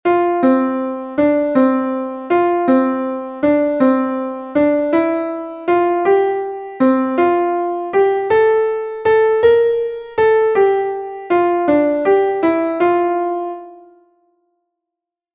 Einstimmige Melodie im Violinschlüssel, , , mit der 1.